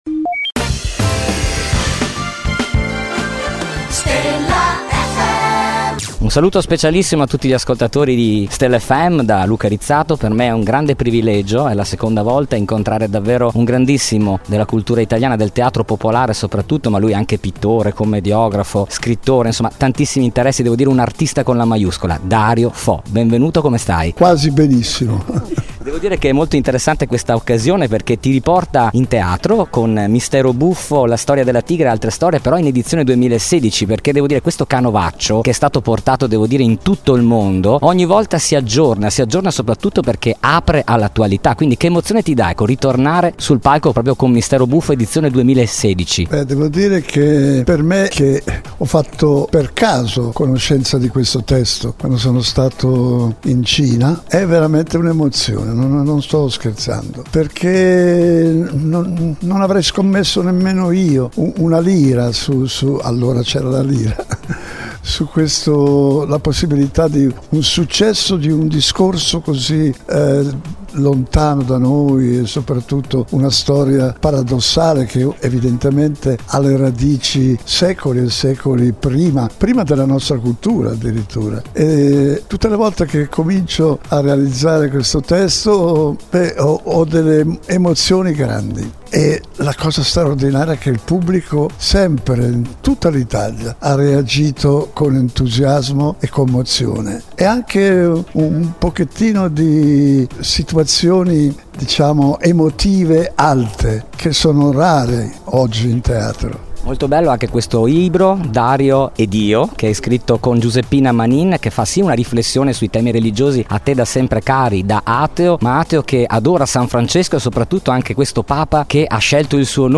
Questa intervista è stata realizzata quest’anno al Teatro Geox di Padova proprio prima di salire sul palco per “Mistero Buffo 2016” e vuole essere un omaggio alla memoria e al talento di uno dei grandi protagonisti del teatro, della cultura e della vita civile del nostro Paese.